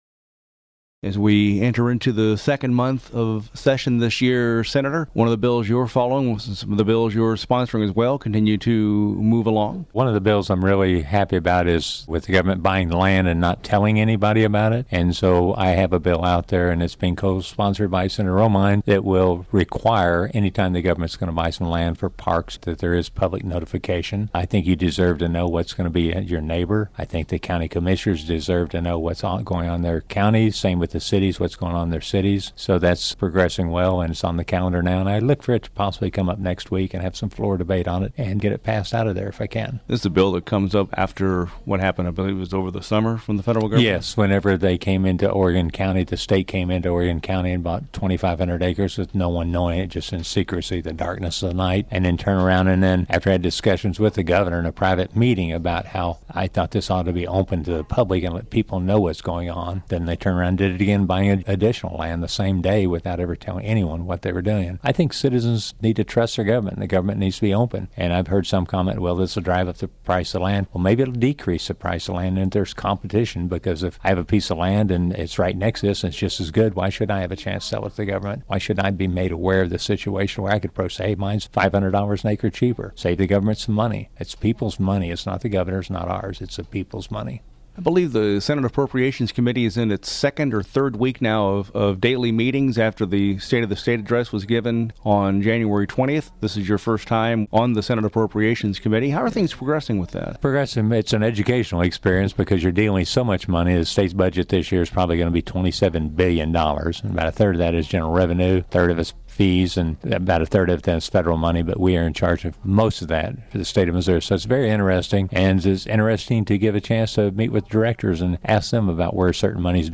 The audio below is a full-length interview with Sen. Cunningham — also available via podcast — for the week of Feb. 1, 2016.